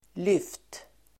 Uttal: [lyf:t]